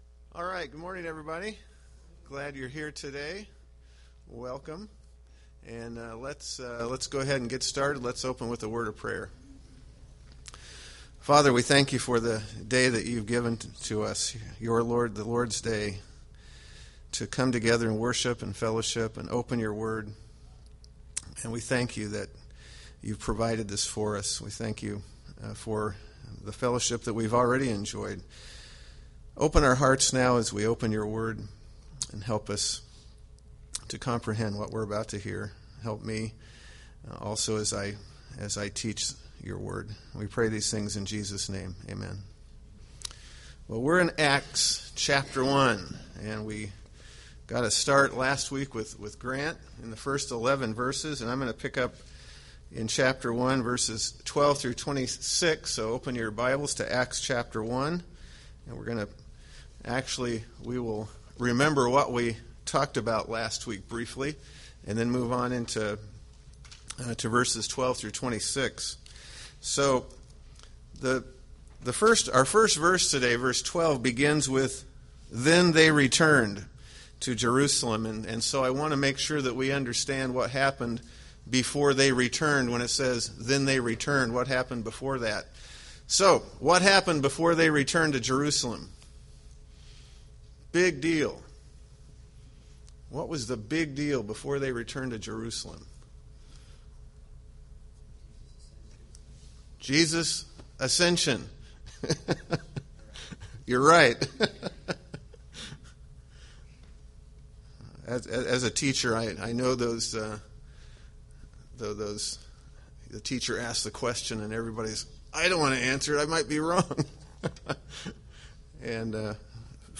Date: Nov 10, 2013 Series: Acts Grouping: Sunday School (Adult) More: Download MP3